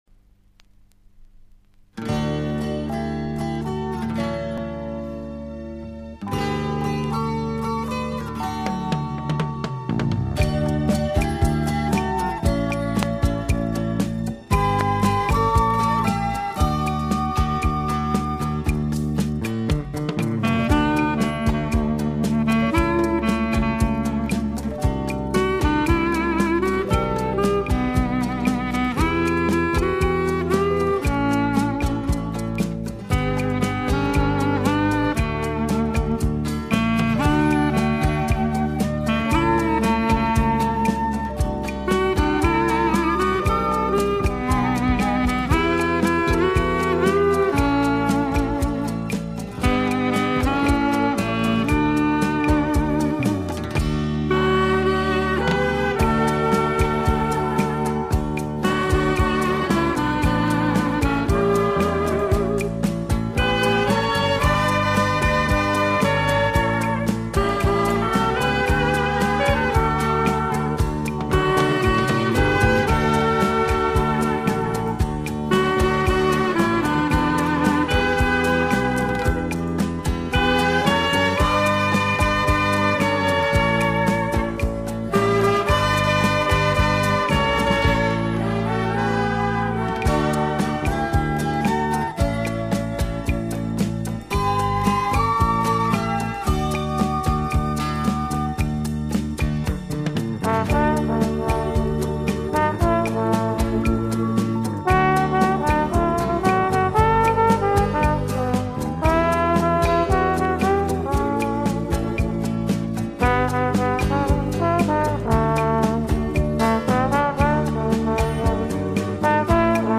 Disco time